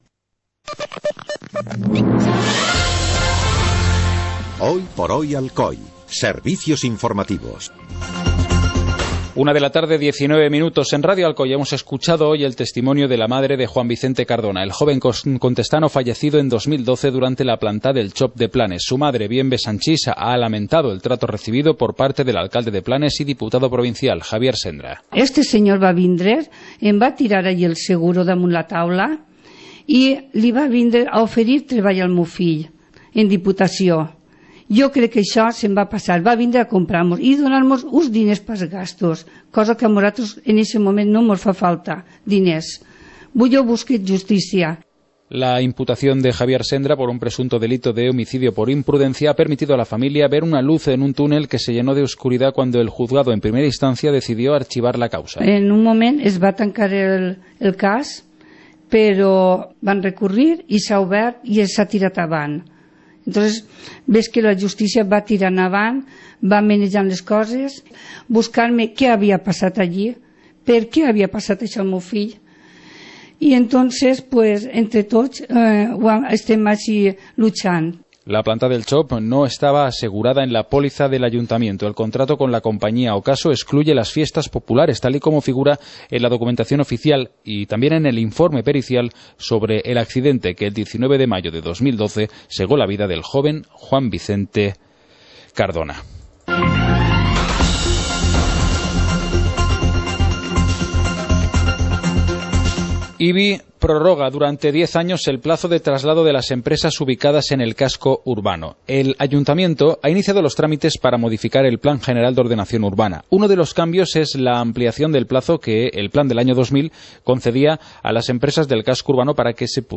Informativo comarcal - jueves, 27 de noviembre de 2014